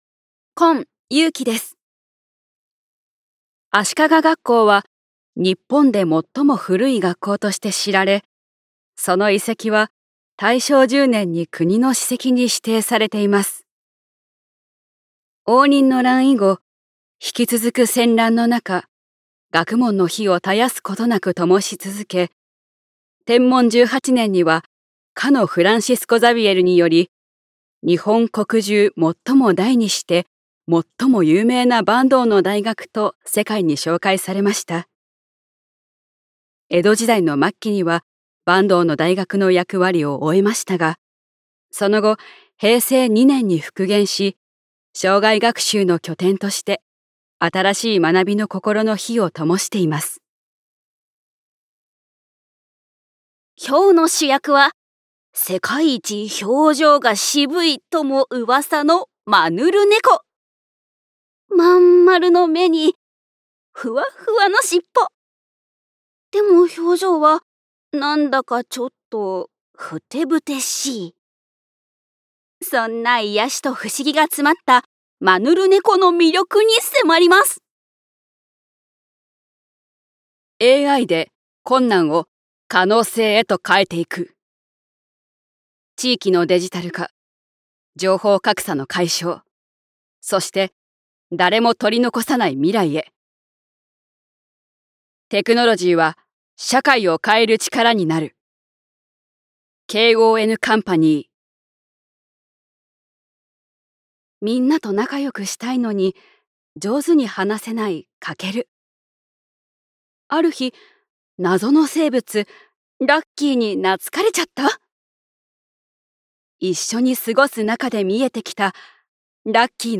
◆ナレーション